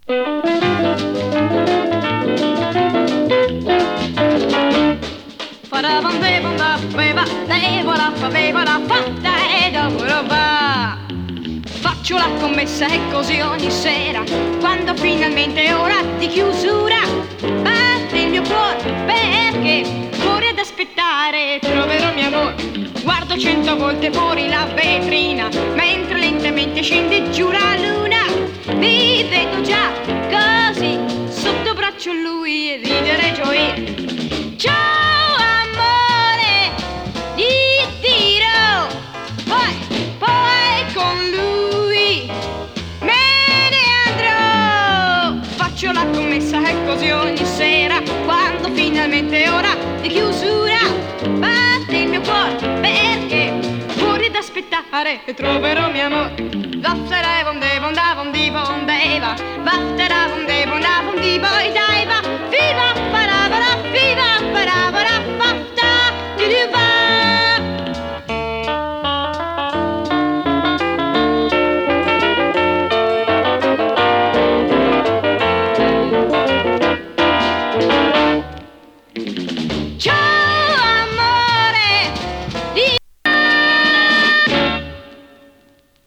試聴 イタリア産 ヤサグレ キャバレー スウィング
＊音の薄い部分で軽いチリパチ・ノイズ。
MONO盤です。